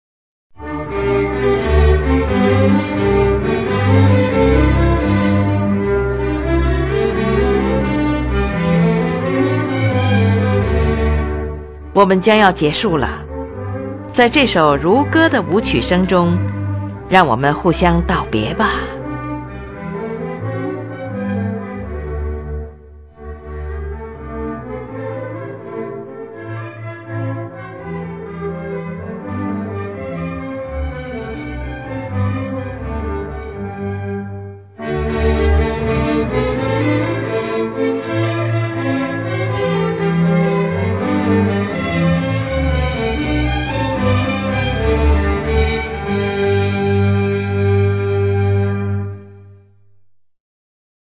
in F Major
是一部管弦乐组曲
乐器使用了小提琴、低音提琴、日耳曼横笛、法兰西横笛、双簧管、圆号、小号等